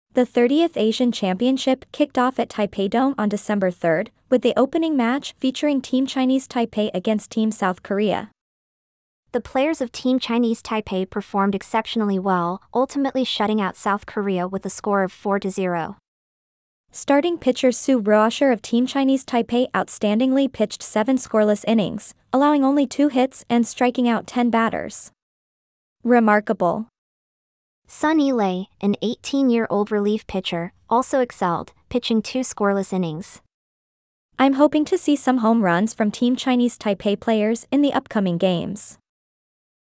英文對話練習